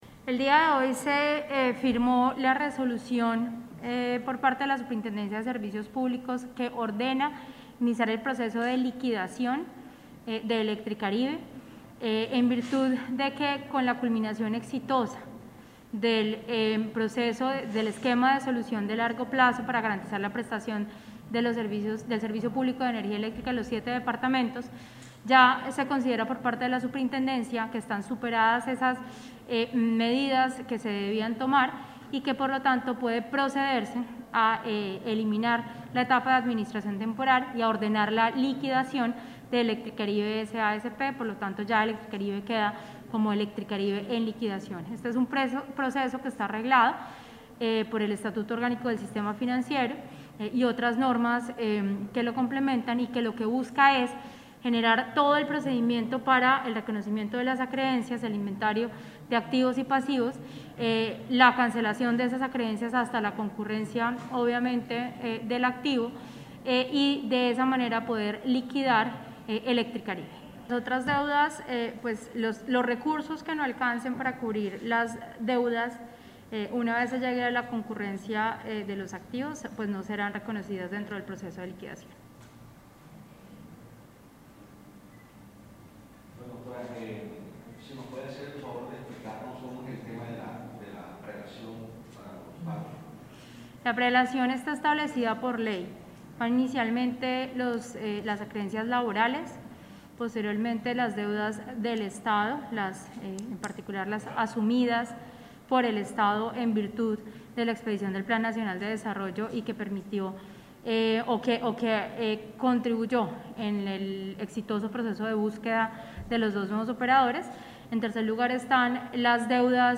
Declaraciones de la superintendente Natasha Avendaño García